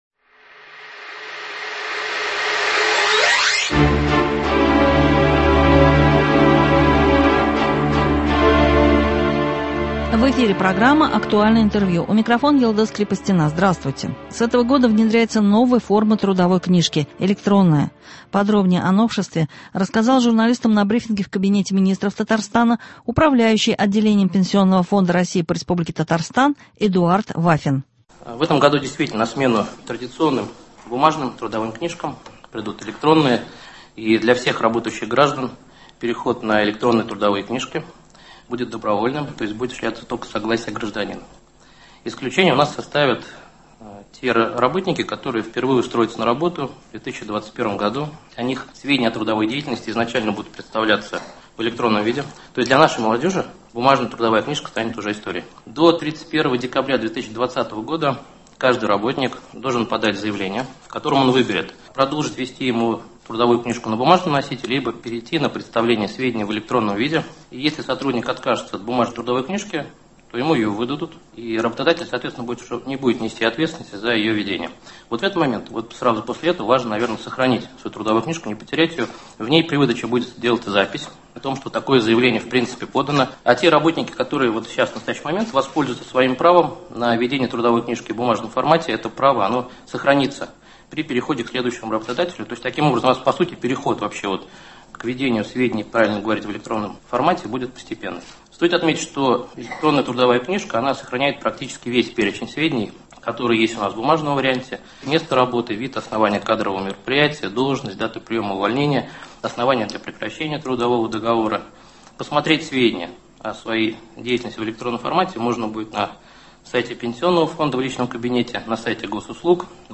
«Актуальное интервью». 12 февраля.
Подробнее о новшестве рассказал журналистам на брифинге в Кабинете министров Татарстана Управляющий Отделением Пенсионного Фонда России по Республике Татарстан Эдуард Вафин.